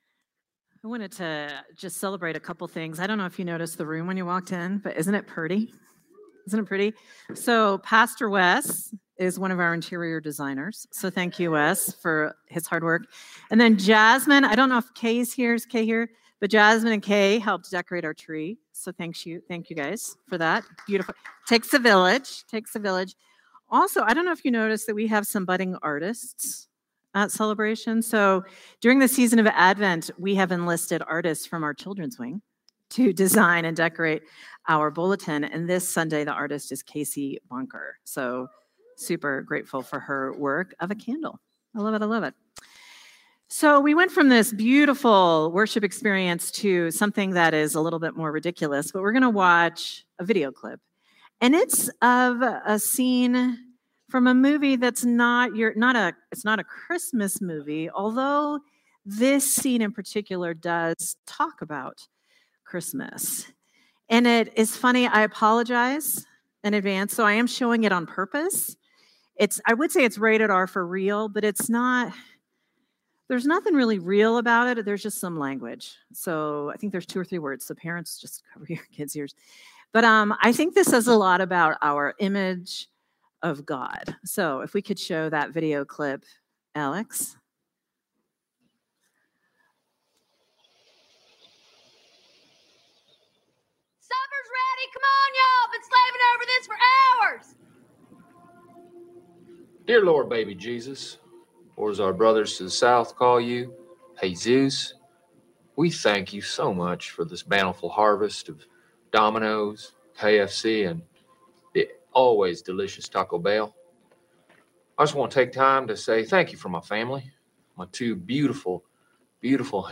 Sermon from Celebration Community Church on December 7, 2025